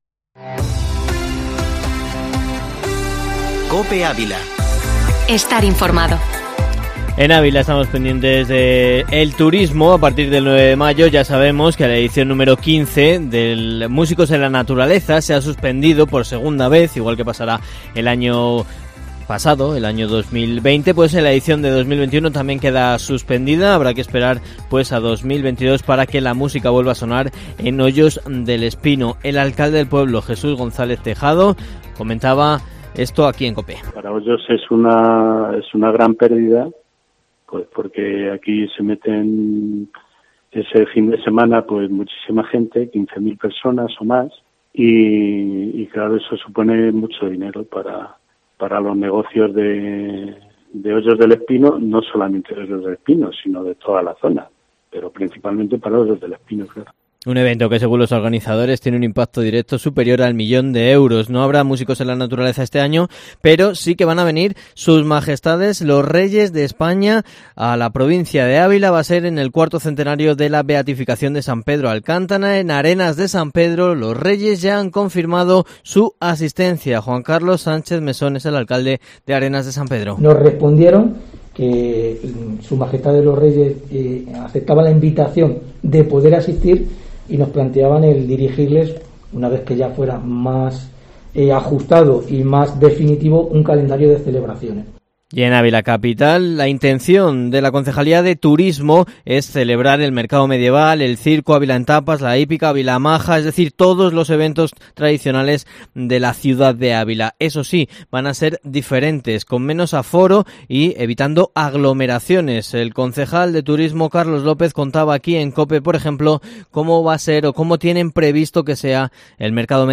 Informativo matinal Herrera en COPE Ávila 29/04/2021